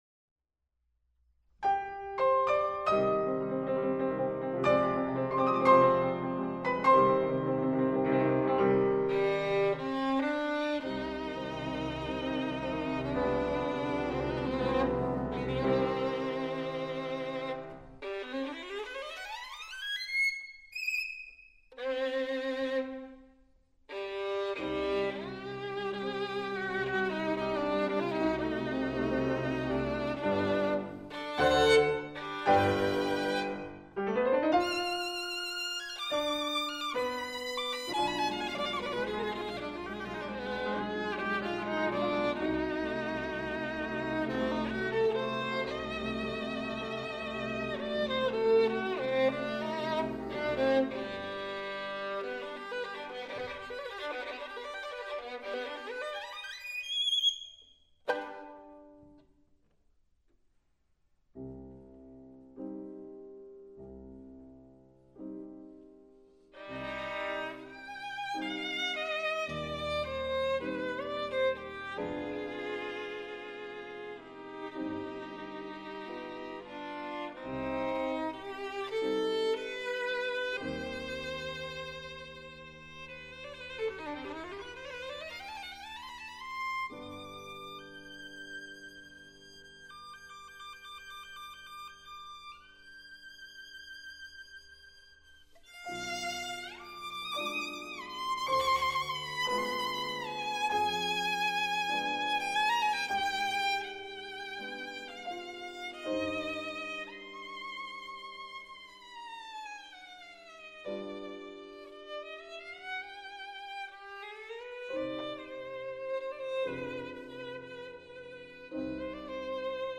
violino
pianoforte